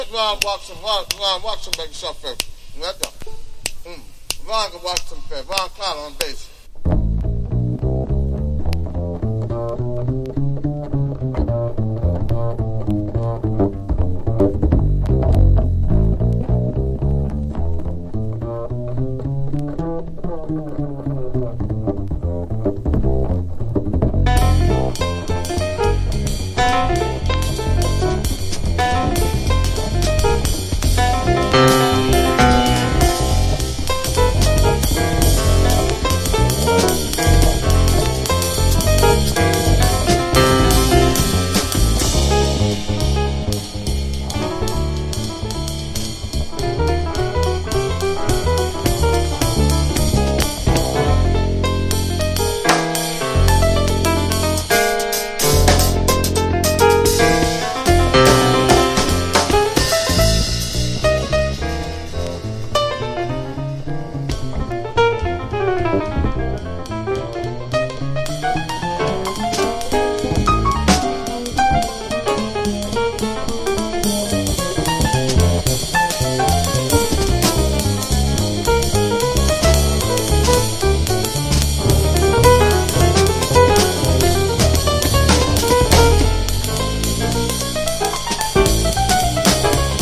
MODERN JAZZ